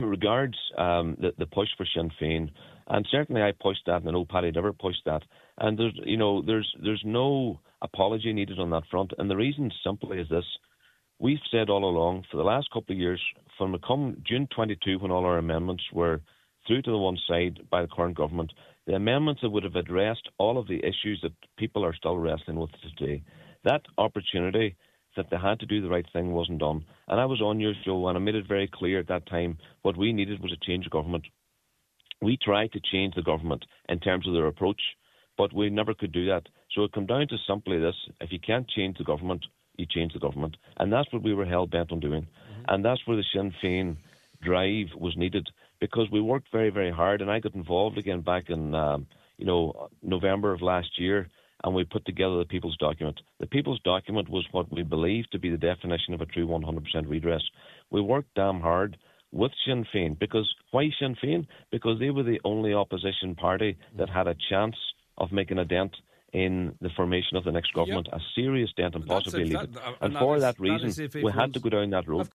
He told the Nine til Noon Show that nothing will change under an expected Fianna Fail and Fine Gael led Government: